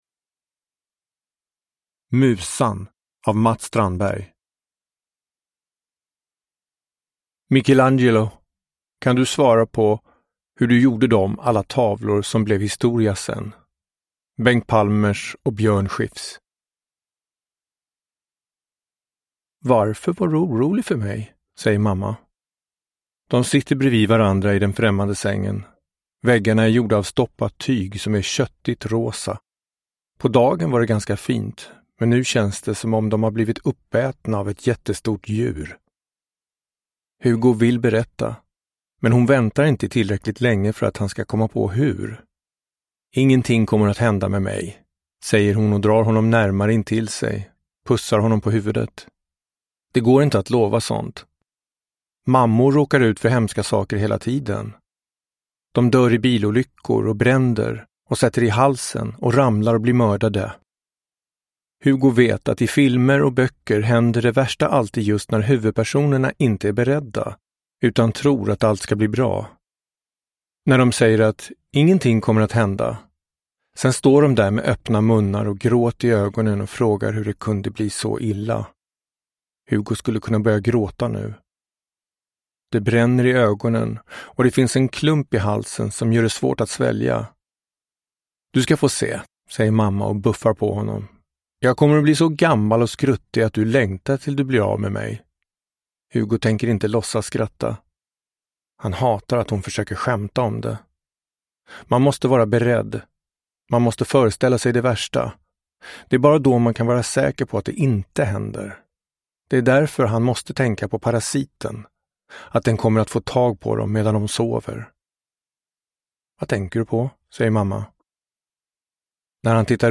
Musan – Ljudbok